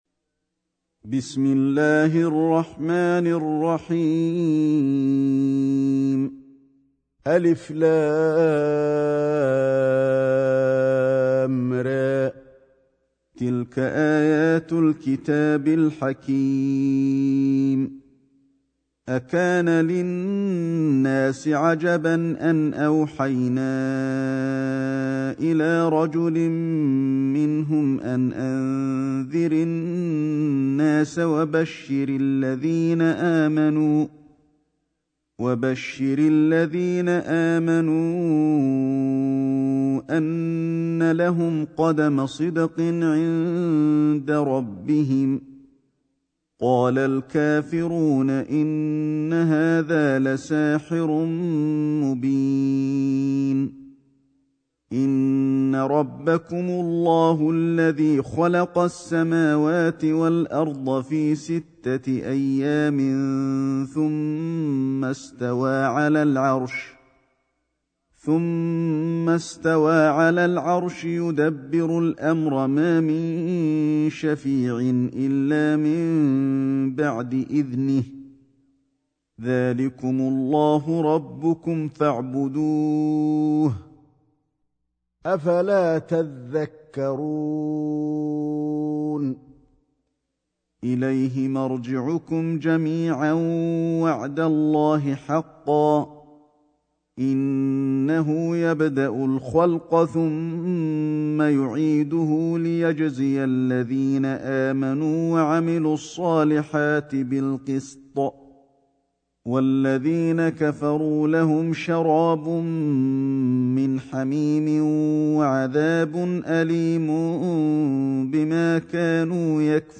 سورة يونس > مصحف الشيخ علي الحذيفي ( رواية شعبة عن عاصم ) > المصحف - تلاوات الحرمين